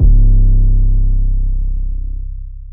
YM 808 14.wav